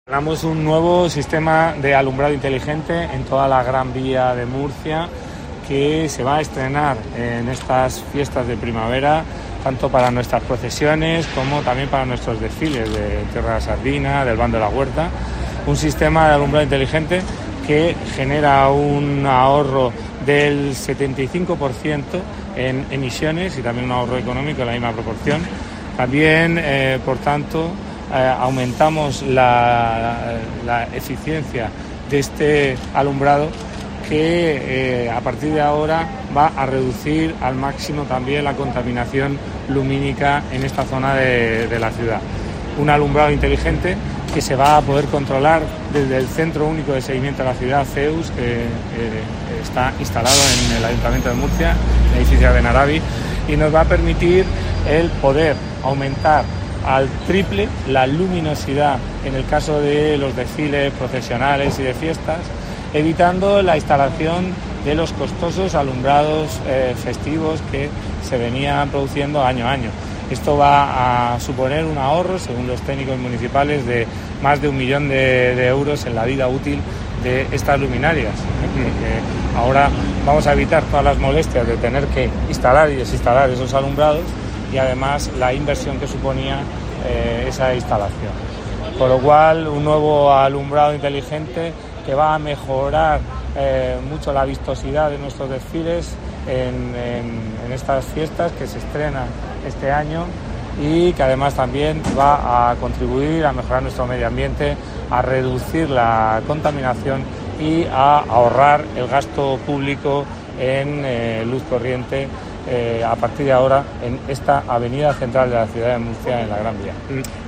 José Guillén, concejal delegado de Desarrollo Urbano y Ciudad Inteligente